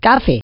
voice_coffee.wav